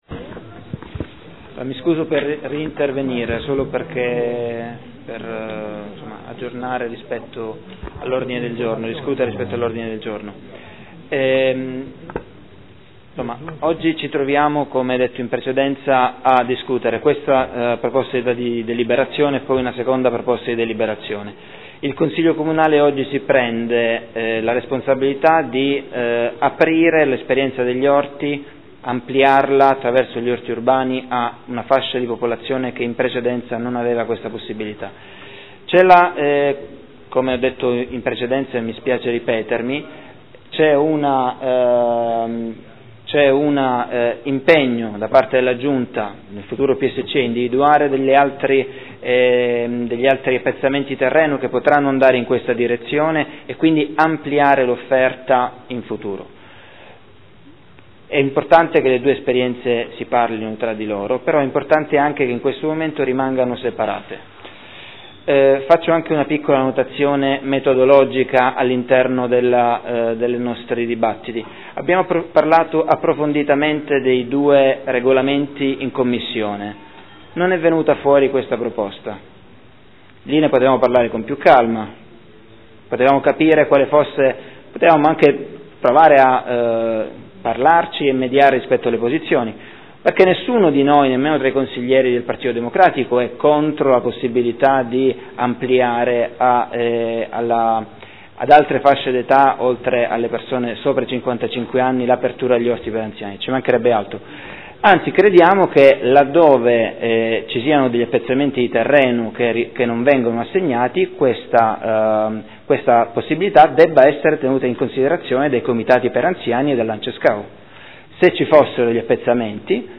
Tommaso Fasano — Sito Audio Consiglio Comunale